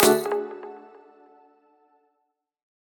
match-leave.mp3